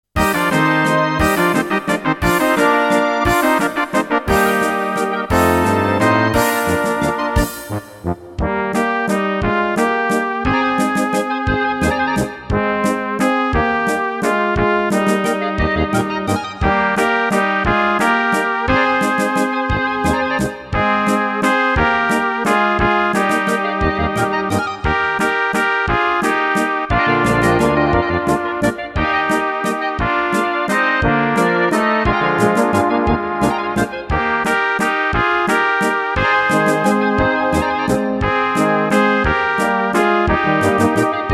Rubrika: Národní, lidové, dechovka
Karaoke